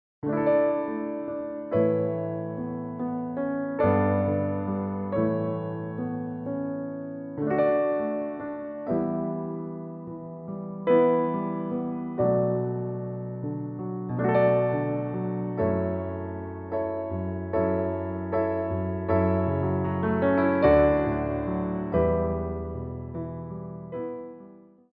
4/4 (16x8)